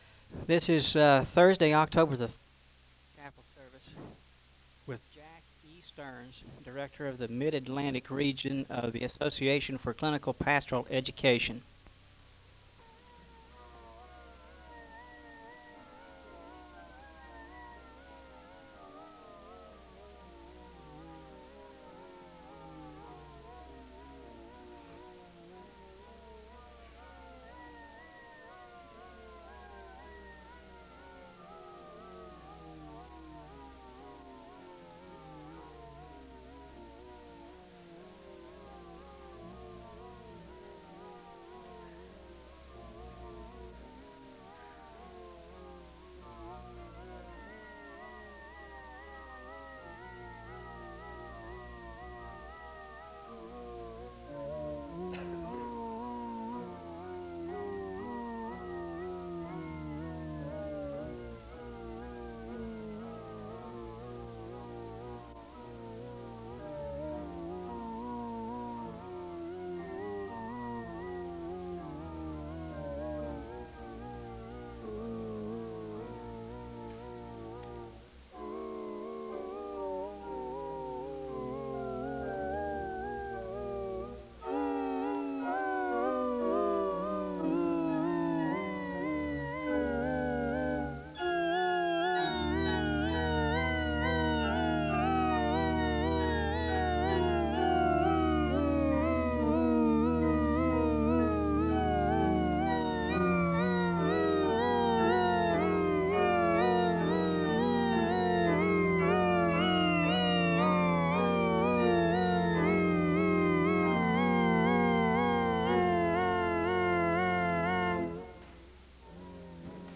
A hymn is played (0:14-3:15).
A word of prayer is given, followed by a hymn (3:16-6:40).
A hymn is played (cut) (11:37-11:42).
A word of prayer concludes the service (28:22-30:37).